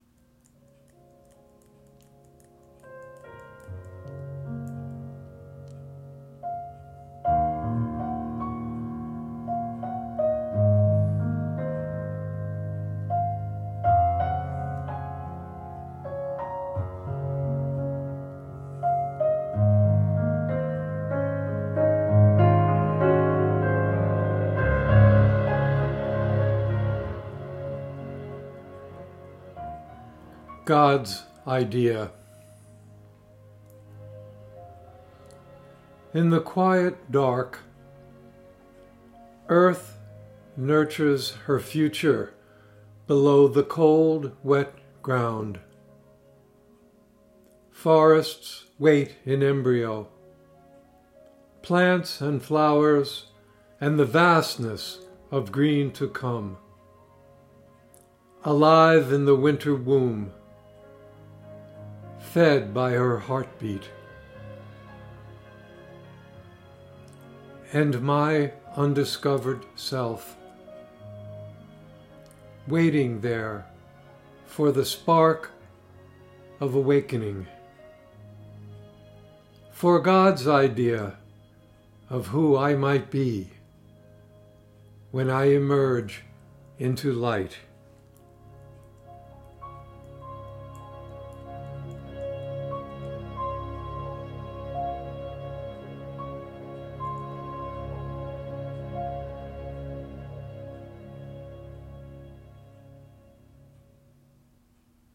Reading of “God’s Idea” with music by Paul Cardall